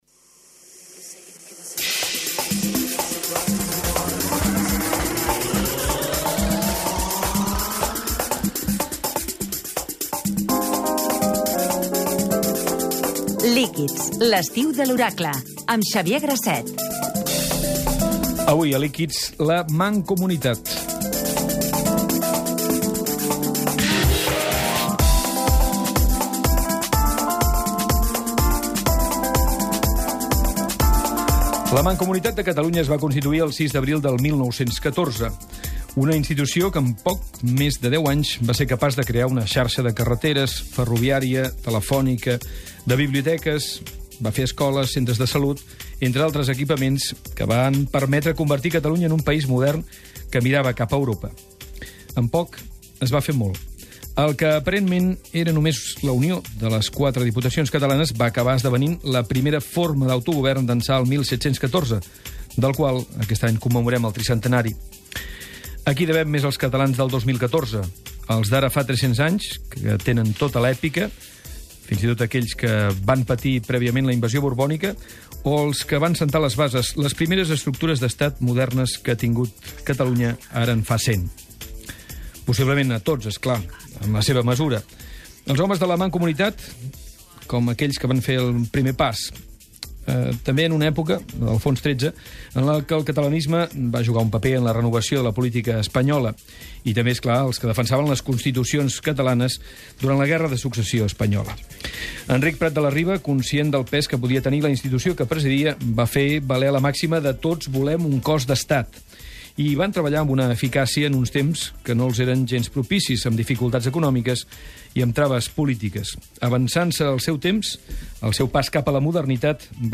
100 anys de la Mancomunitat. Tertúlia a Líquids